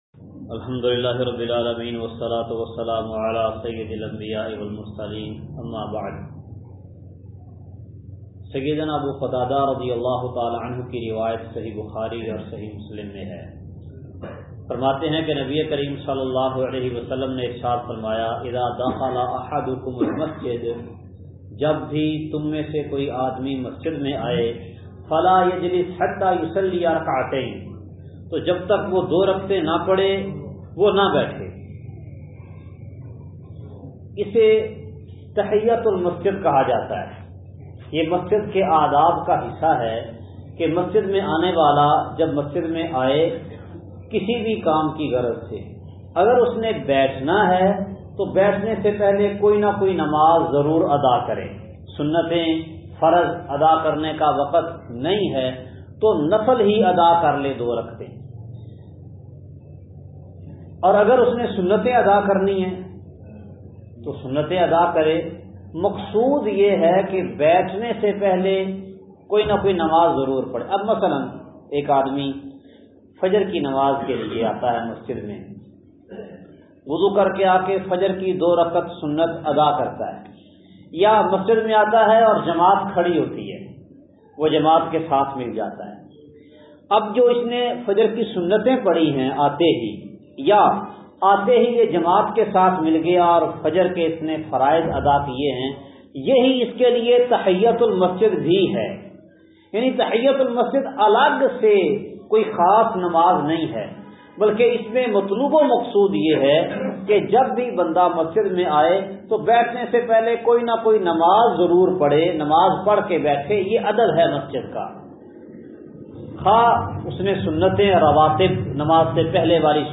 درس کا خلاصہ